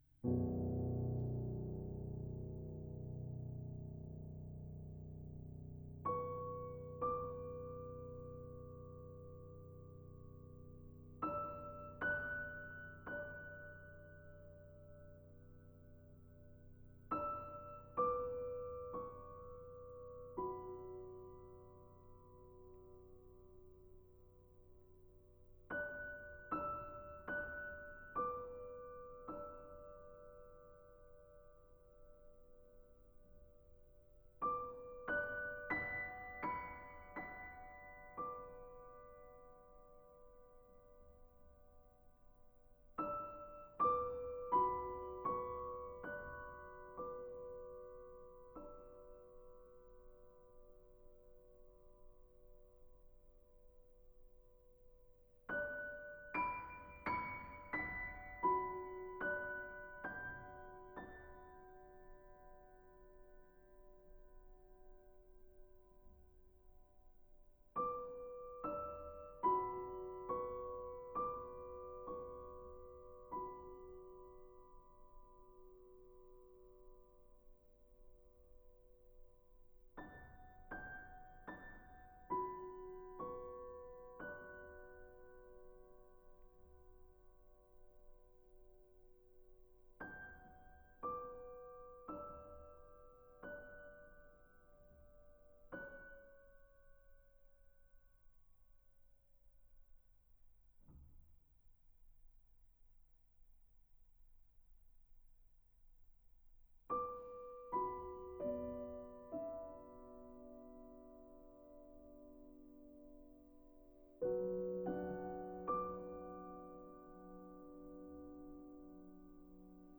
To my ears, it's difficult to tell the original and +6dB versions apart: they both sound fine, though obviously one is slightly louder than the other.